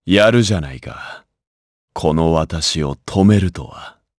Lusikiel-Vox_Dead_jp_b.wav